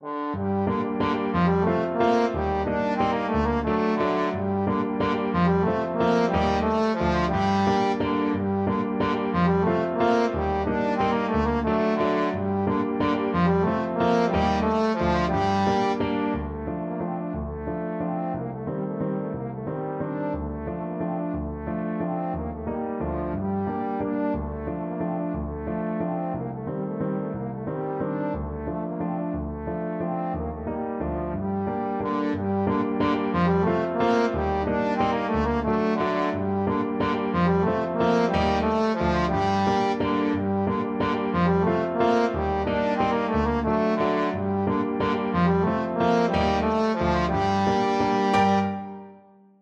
Trombone
G minor (Sounding Pitch) (View more G minor Music for Trombone )
3/4 (View more 3/4 Music)
One in a bar . = c. 60
Traditional (View more Traditional Trombone Music)
world (View more world Trombone Music)